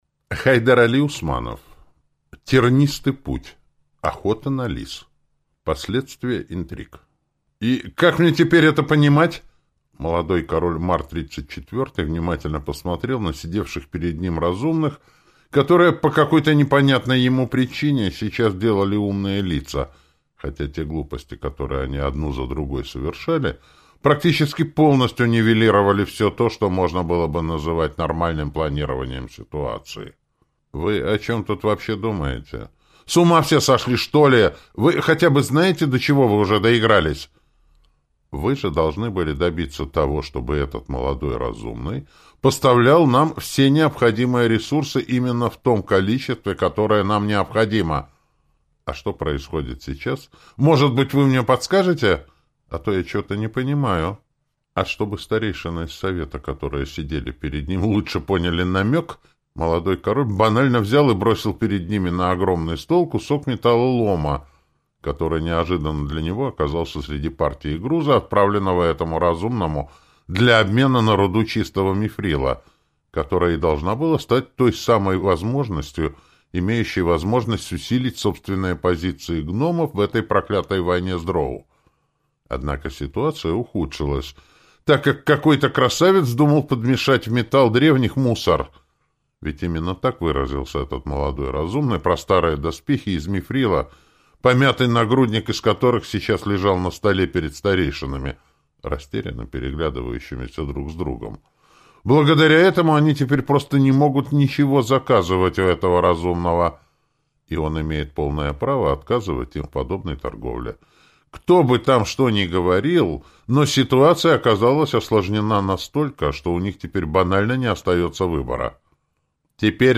Аудиокнига Тернистый путь. Охота на лис | Библиотека аудиокниг